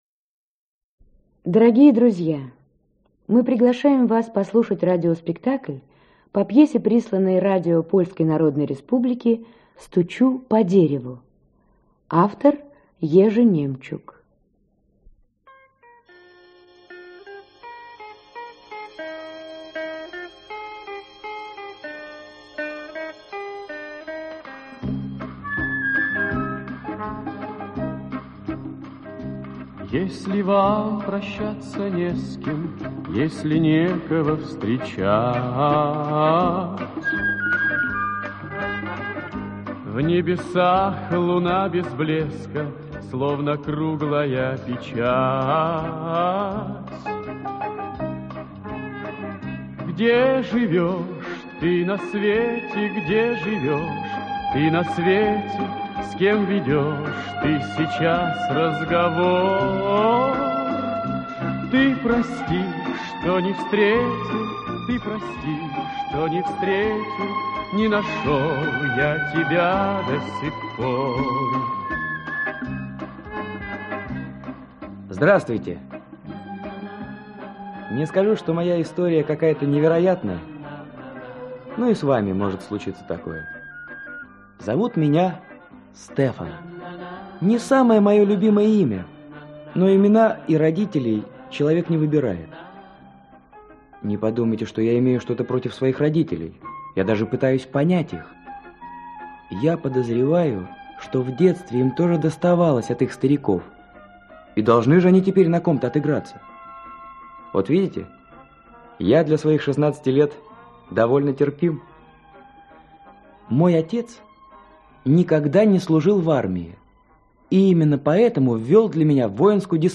Аудиокнига Стучу по дереву | Библиотека аудиокниг
Aудиокнига Стучу по дереву Автор Ежи Немчук Читает аудиокнигу Актерский коллектив.